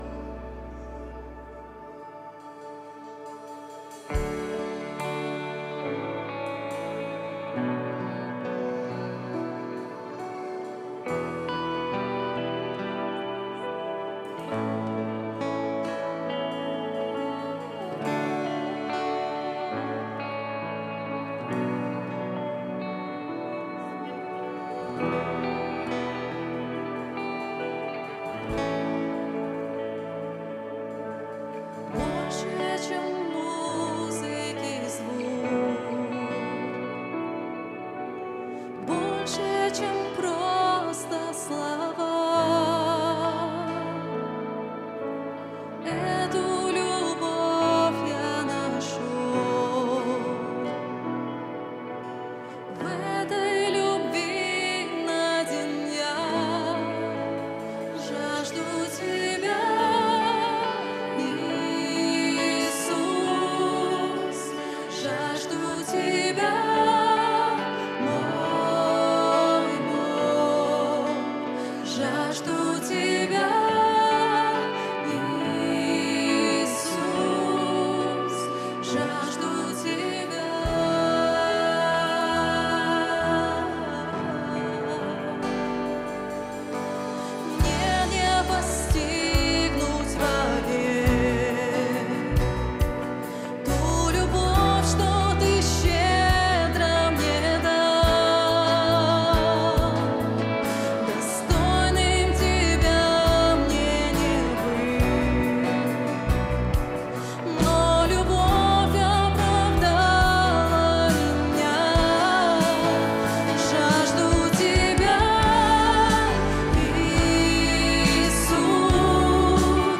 123 просмотра 341 прослушиваний 5 скачиваний BPM: 70